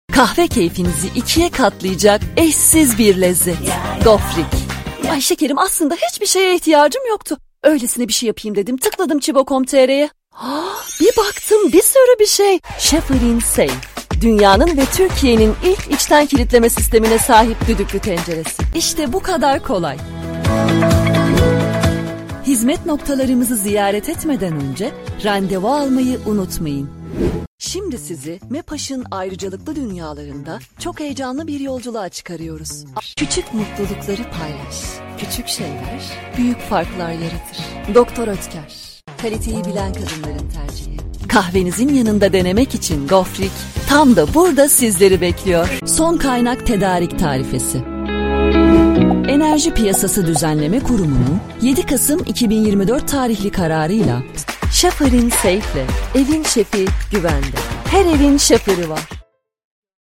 Presentations / Educational
female presentation voiceover